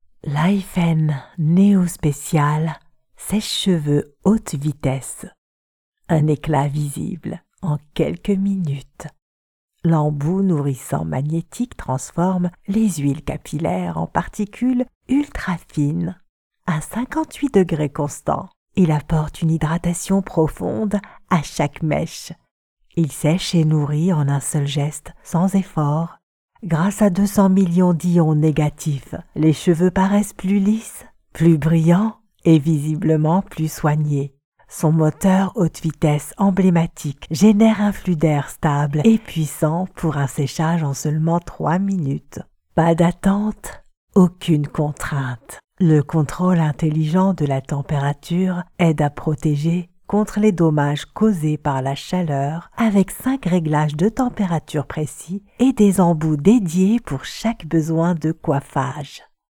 Female Voice Over Talent, Artists & Actors
Yng Adult (18-29) | Adult (30-50)